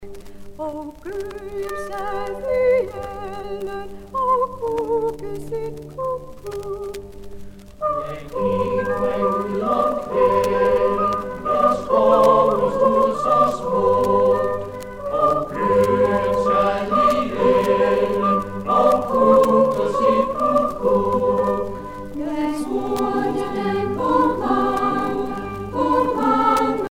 Danske folkesange